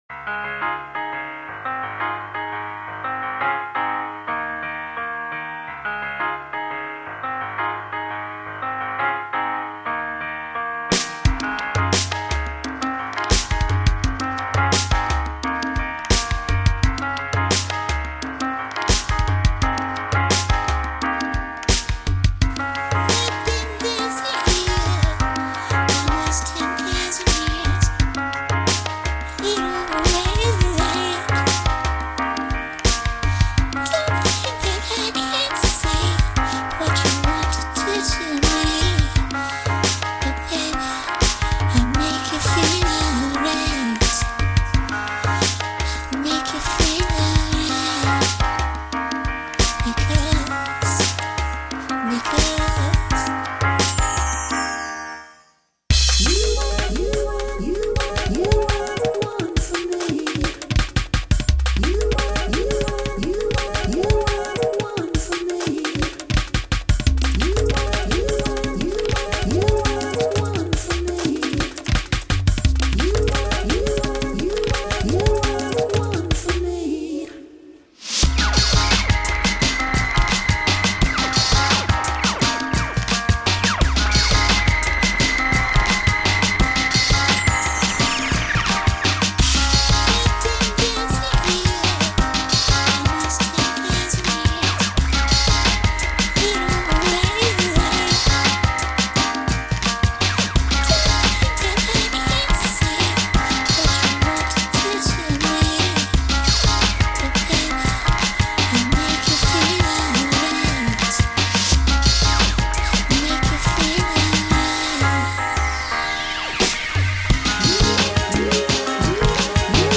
POP SOUNDS